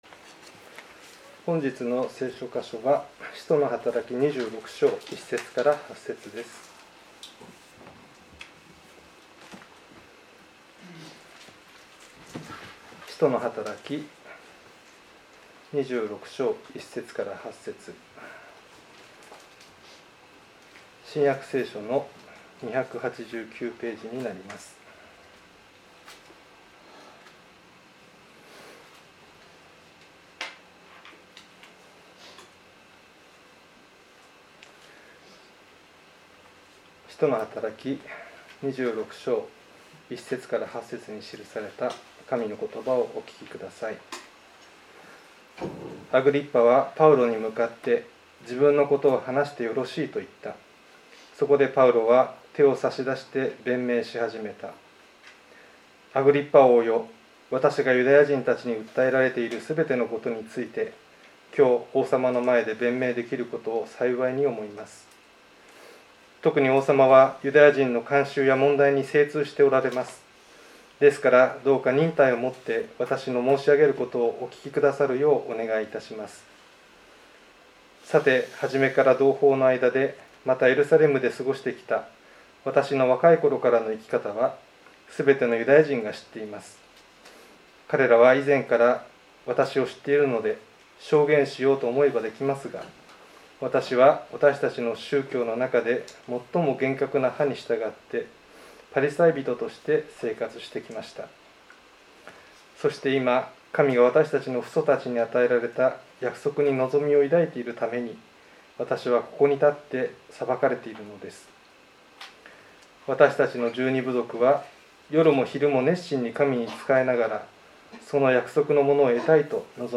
5月第2聖日礼拝のメッセージの配信です。